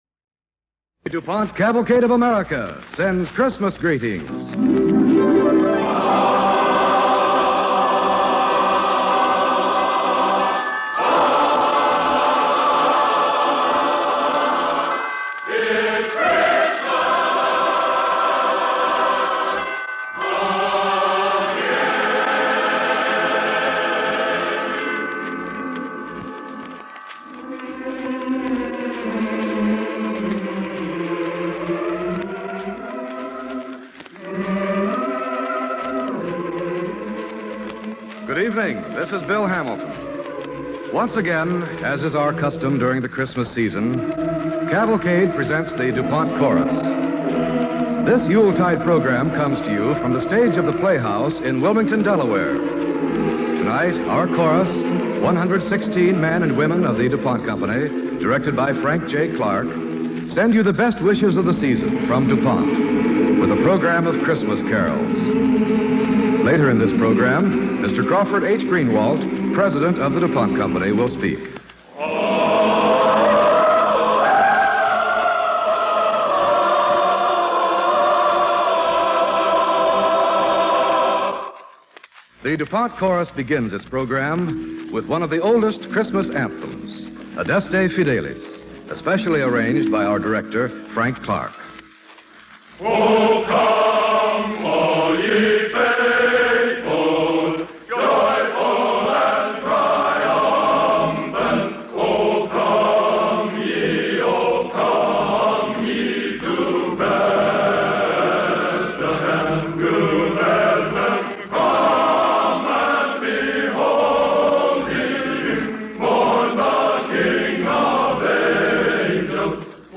The Du Pont Chorus sings Christmas Carols, with Adeste Fideles